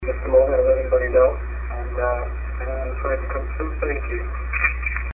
EVP's From Some Very Friendly Spirits